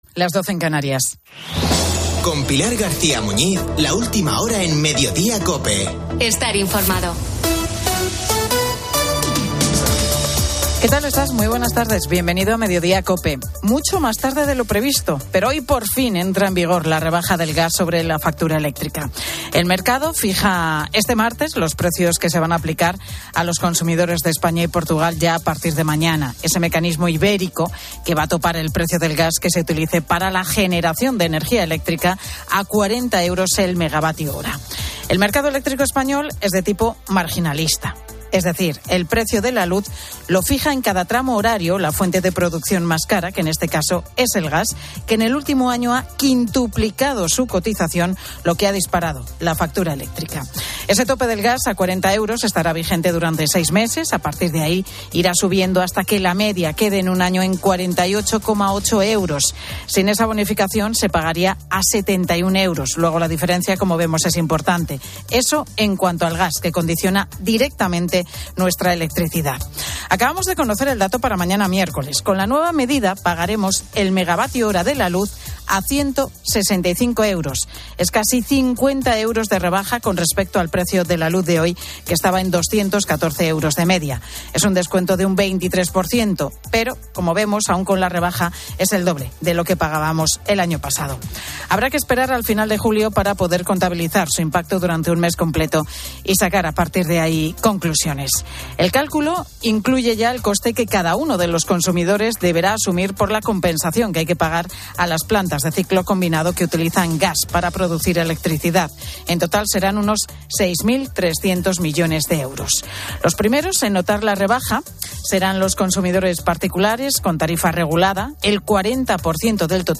AUDIO: Mediodía COPE hora completa de 13 a 14 es un programa presentado por Pilar García Muñiz de lunes a viernes de 13.00h. a 16.00h (14/06/2022) -...